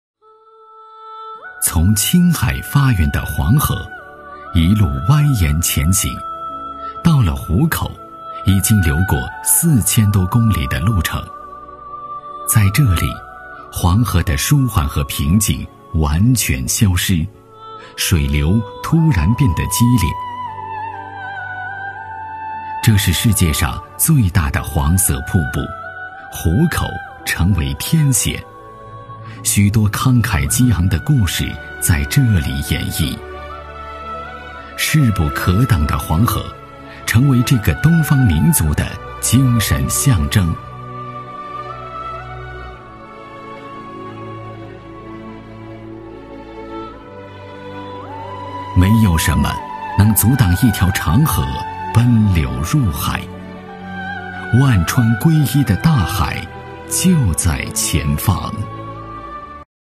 男110号